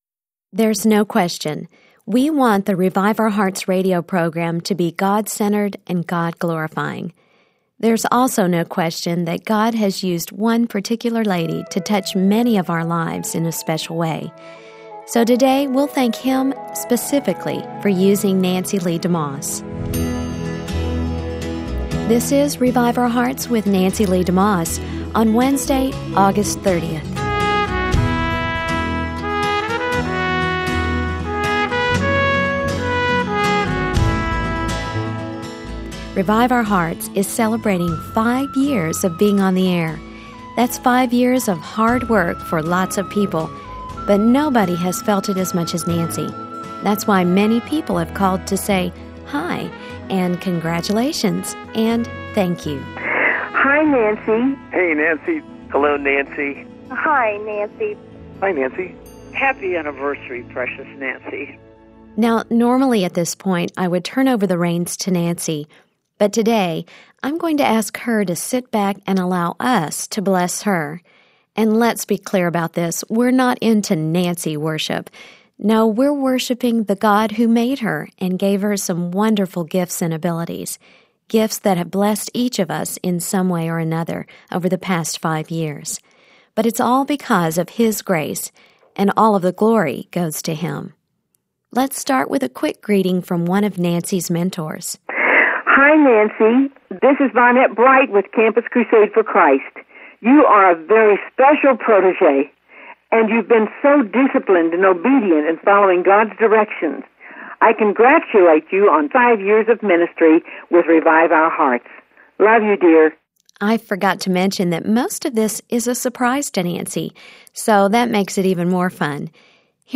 Revive Our Hearts is celebrating our 5th anniversary on the air this week. Join in for messages and stories from listeners and guests.